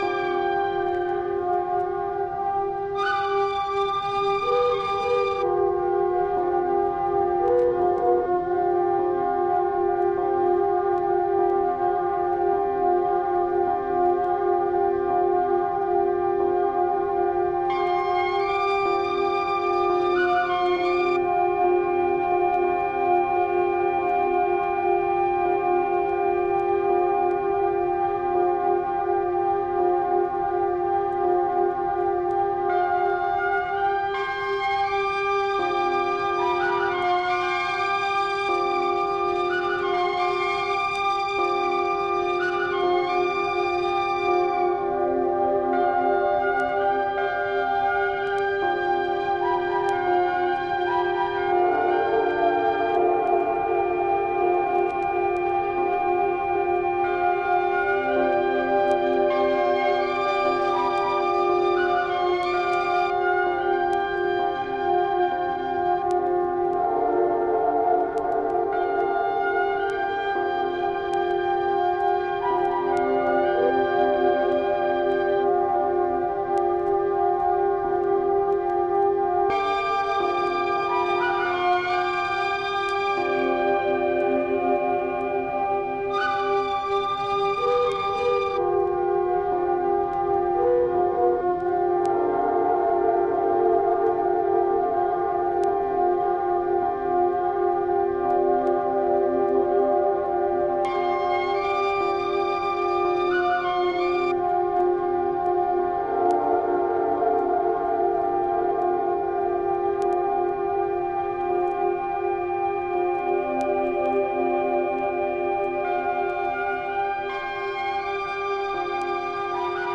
Recordings of flutes and electronics
downtown NYC minimalism and new age ambience